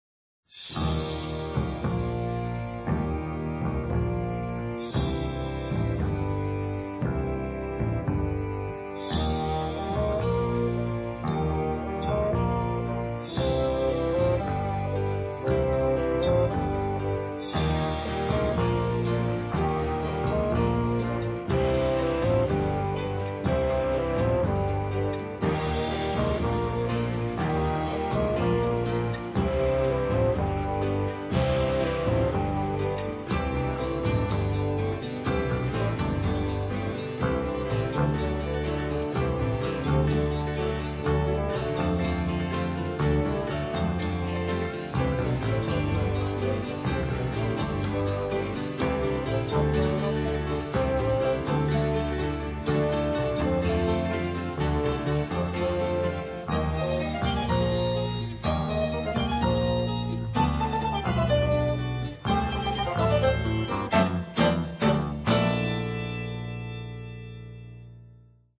Stereo, 1:08, 64 Khz, (file size: 537 Kb).